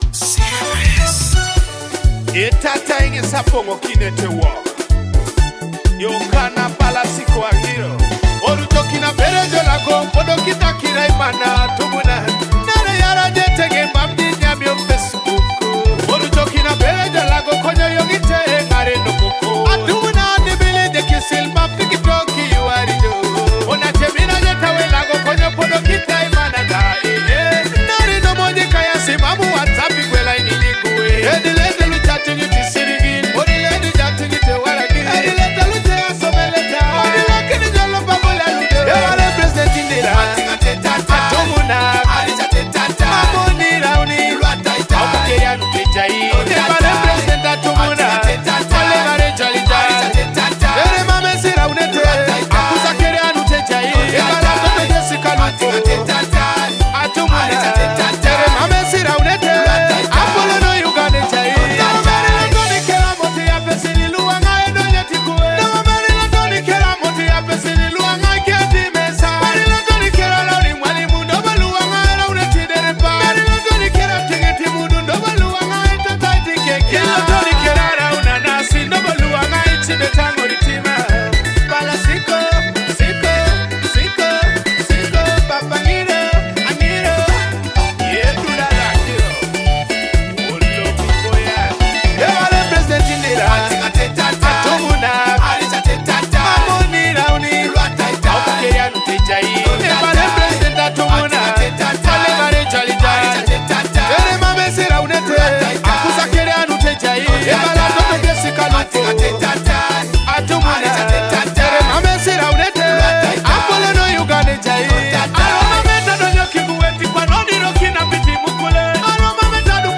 a soulful Teso track celebrating culture